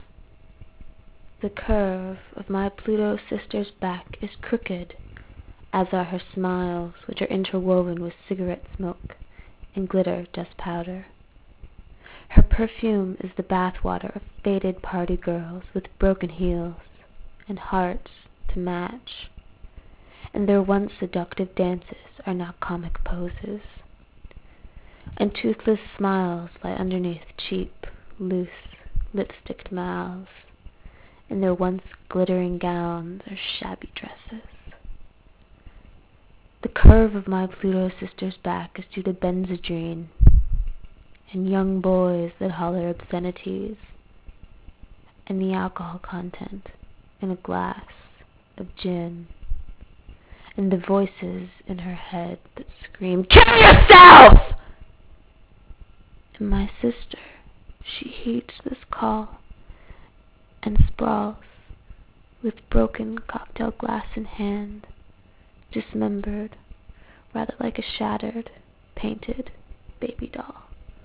Slam Poem { autumn 2002 }